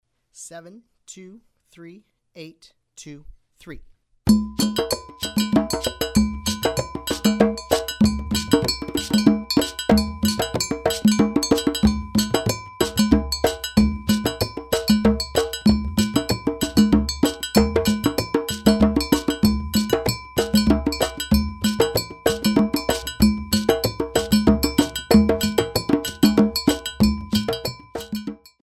The music combines various percussion instruments,
Slow Triple Meter
Slow Triple Meter - 95 bpm